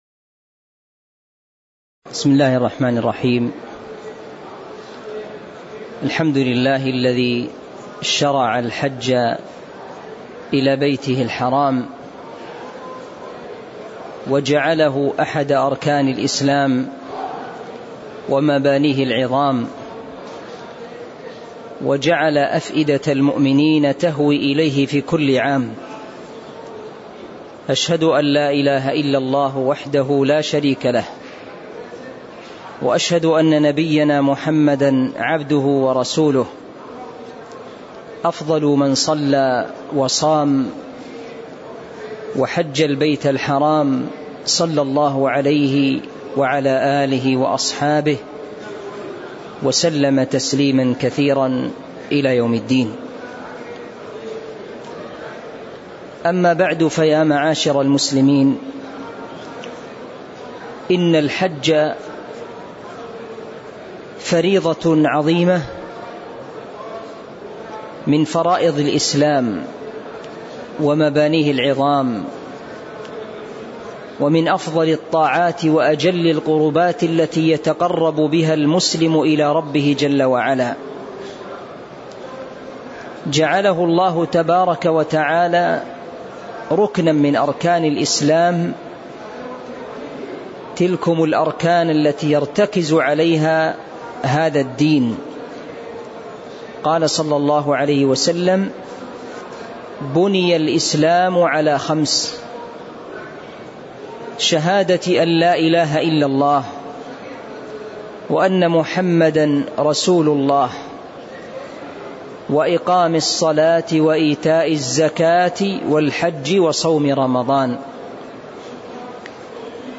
تاريخ النشر ٣ ذو الحجة ١٤٤٣ هـ المكان: المسجد النبوي الشيخ